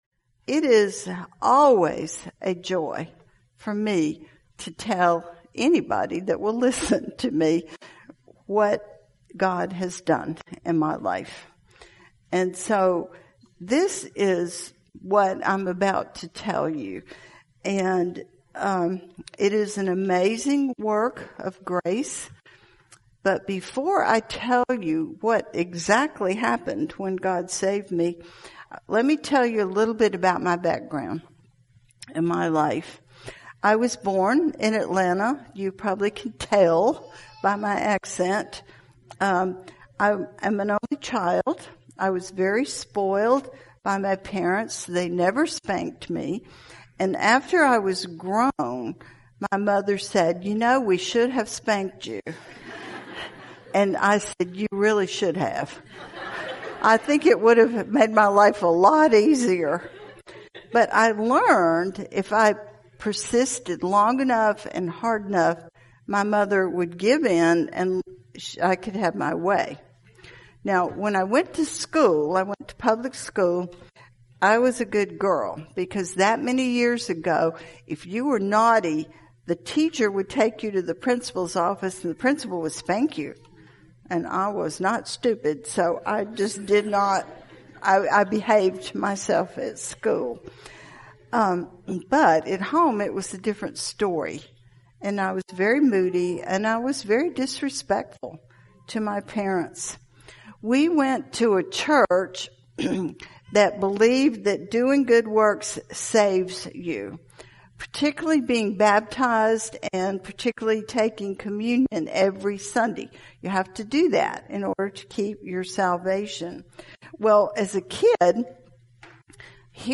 2021 Ladies Conference at Grace Community Church
Category: Testimonies